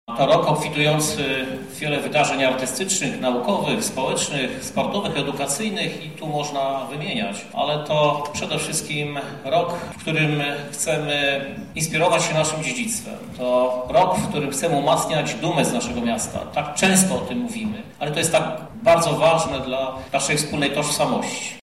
Te wydarzenia mają charakter uspołecznionego projektu – mówi prezydent Miasta lublin Krzysztof Żuk